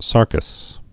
(särkəs)